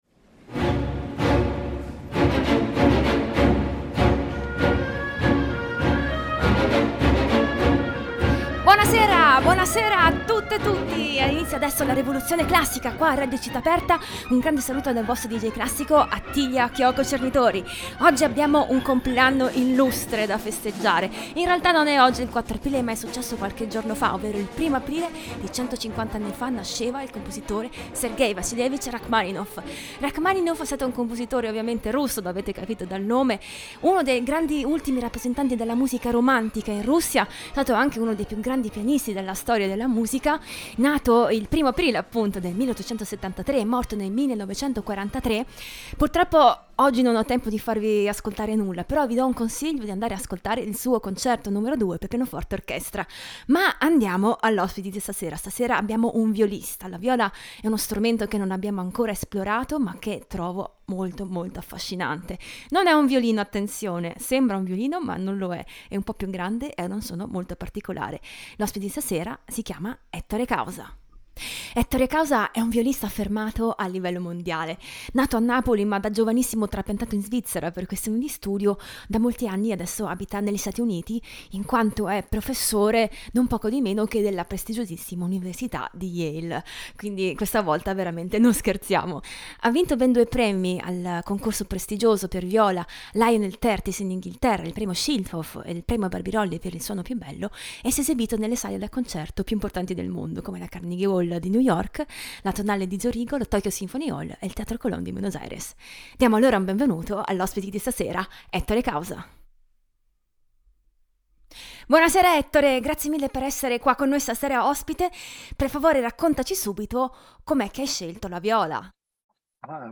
viola
pianoforte